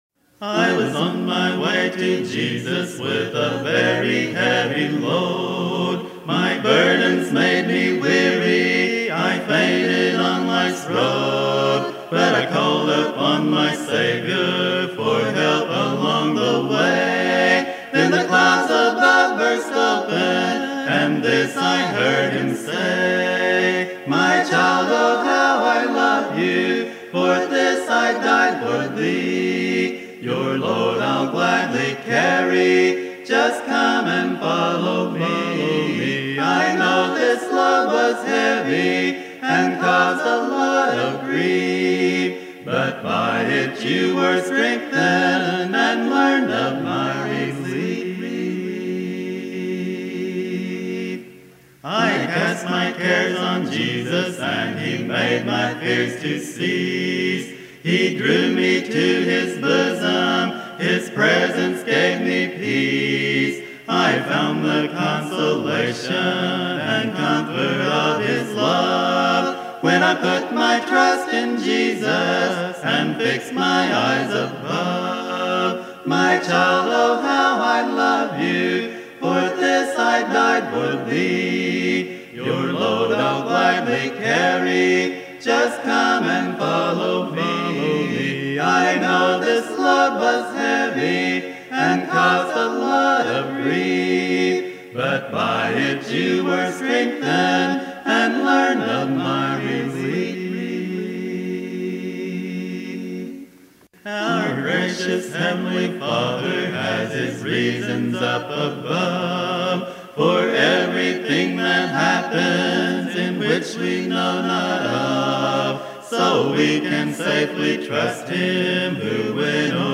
Key: E♭